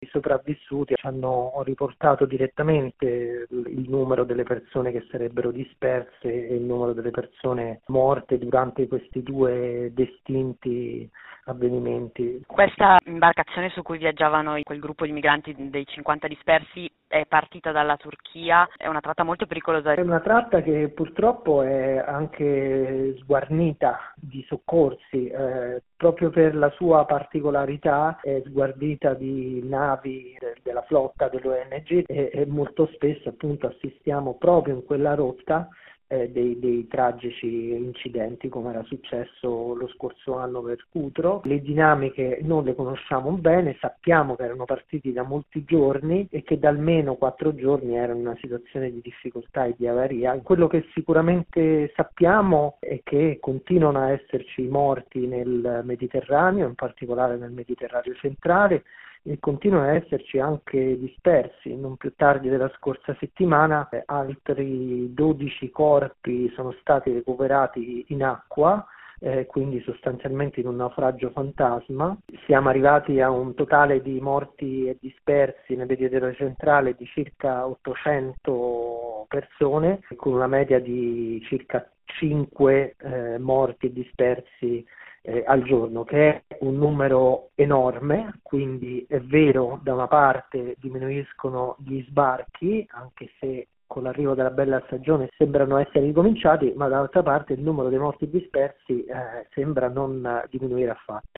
Il racconto della giornata di lunedì 17 giugno 2024 con le notizie principali del giornale radio delle 19.30. Nel Mediterraneo due naufragi nel giro di poche ore riportano l’attenzione su una strage che non ha mai fine; mentre a Gaza non si vede traccia della paventata “pausa tattica”, Netanyahu scioglie il gabinetto di guerra e aumenta così il potere del governo di ultradestra; la maggioranza popolari-socialisti-liberali prova a costruire la nuova Commissione Europea; le logiche securitarie e punitive del governo italiano generano affollamenti nelle carceri che nei casi più esasperati si traducono in suicidi.